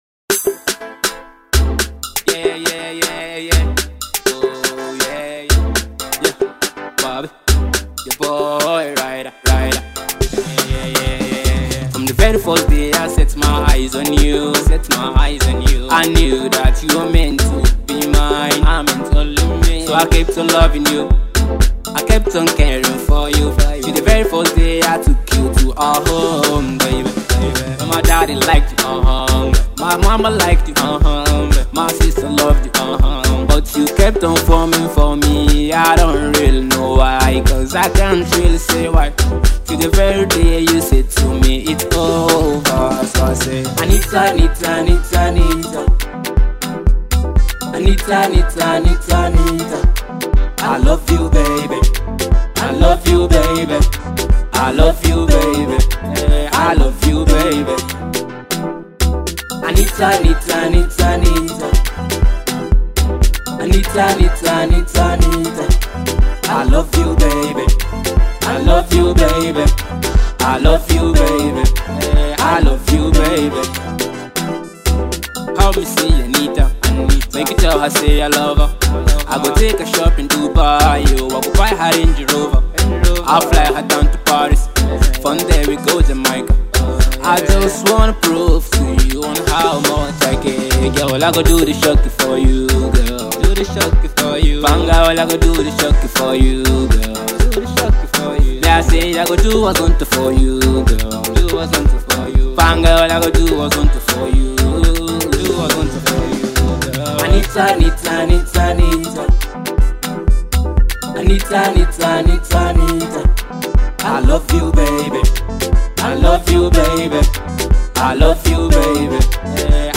groovy hit single